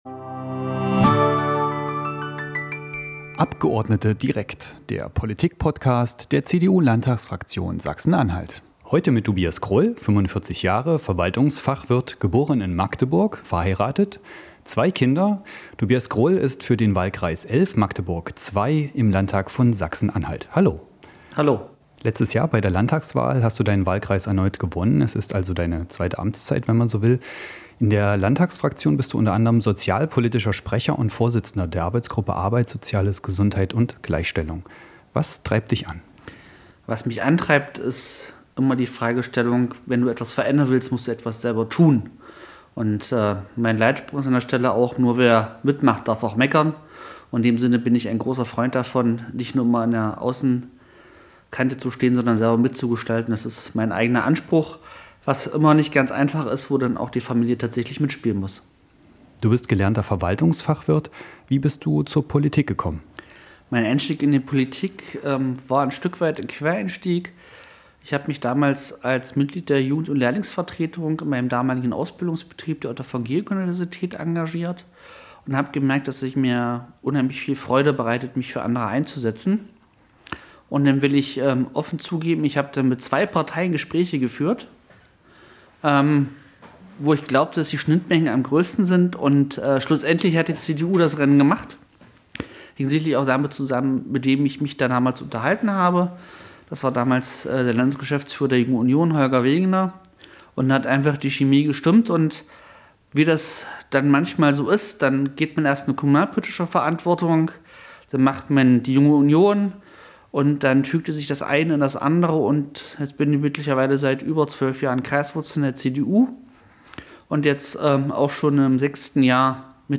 Tobias Krull aus Magdeburg ist im Gespräch des